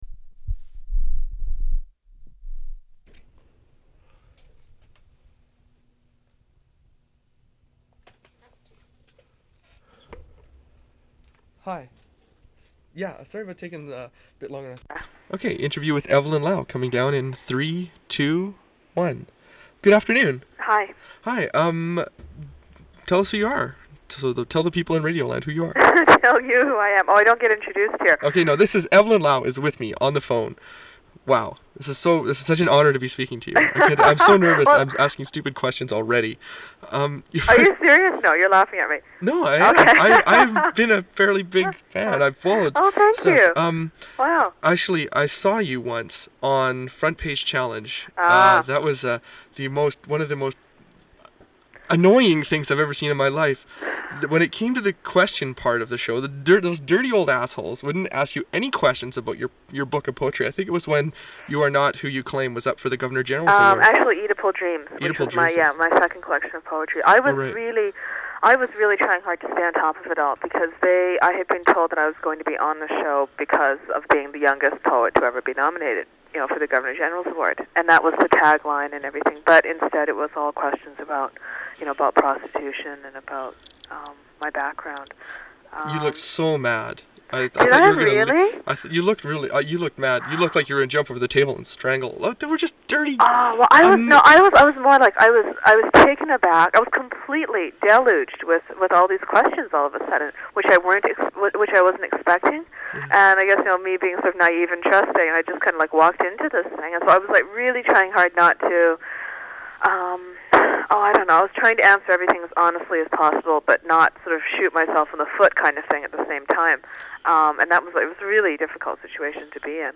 Recording of a phone interview with Vancouver-based writer Evelyn Lau.